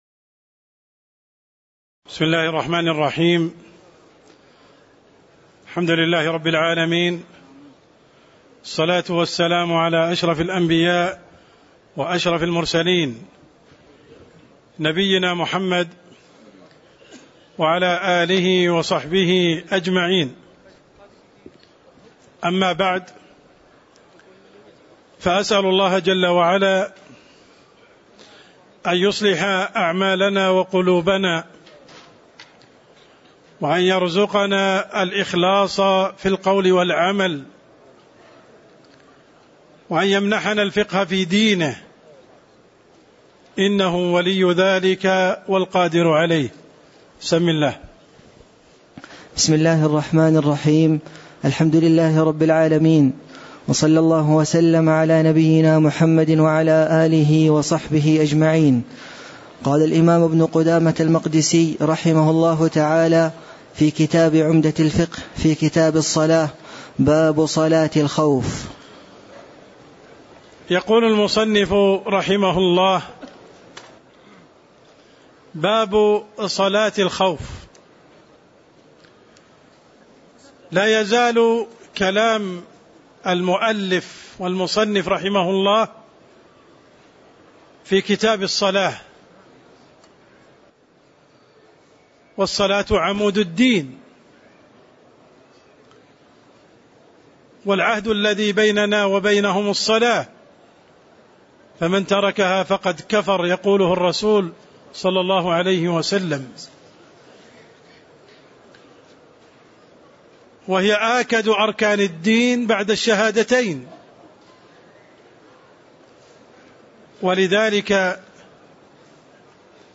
تاريخ النشر ١٧ ربيع الثاني ١٤٣٧ هـ المكان: المسجد النبوي الشيخ: عبدالرحمن السند عبدالرحمن السند باب صلاة االخوف (17)